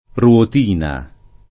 ruotina [ r U ot & na ]